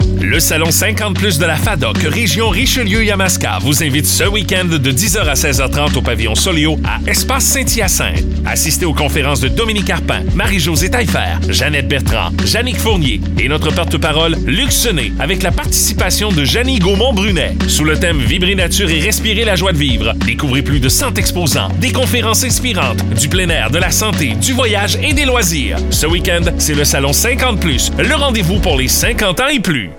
spot publicitaire 30 sec